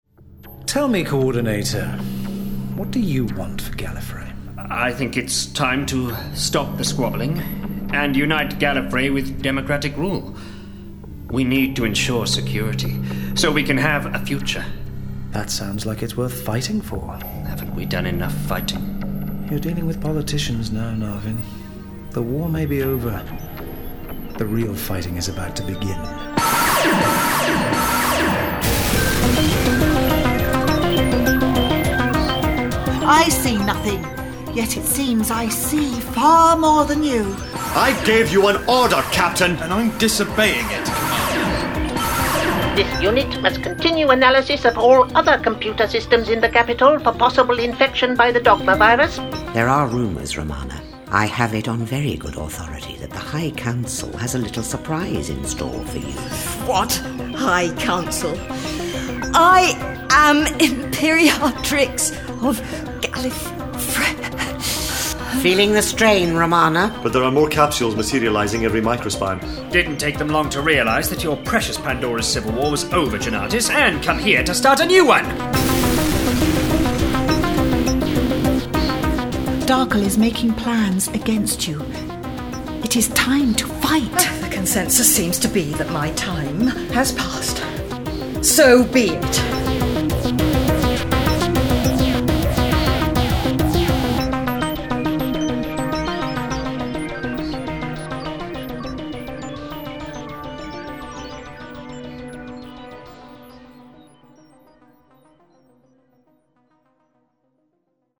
Starring Lalla Ward Louise Jameson